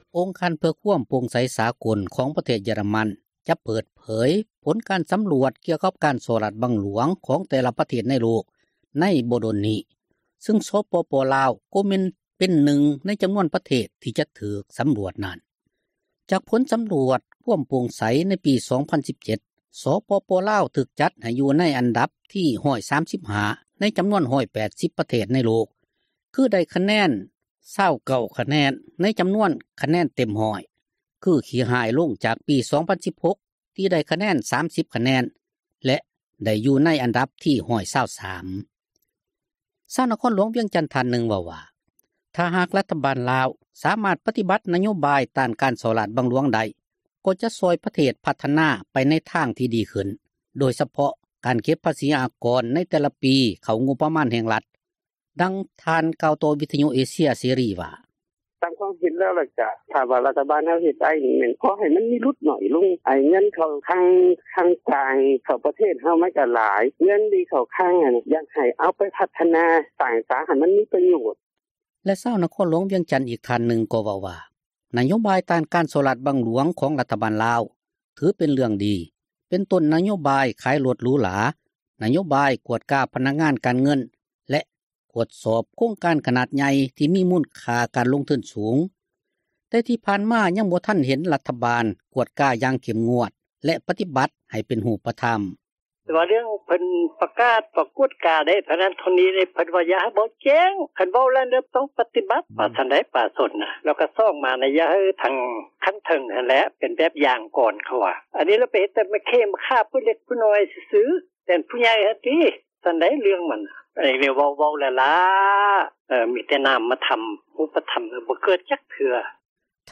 ຊາວນະຄອນຫຼວງວຽງຈັນທ່ານນື່ງ ເວົ້າວ່າ ຖ້າຫາກຣັຖບານລາວ ສາມາດປະຕິບັດນະໂຍບາຍ ຕ້ານການສໍ້ຣາດບັງຫຼວງໄດ້ກໍຈະຊ່ອຍ ປະເທດພັທນາ ໄປໃນທິດທາງທີ່ດີຂືື້ນ ໂດຍສະເພາະການເກັບພາສີອາກອນ ໃນແຕ່ລະປີ ເຂົ້າງົບປະມານຣັຖ, ດັ່ງ ທ່ານກ່າວຕໍ່ວິທຍຸ ເອເຊັຍເສຣີວ່າ: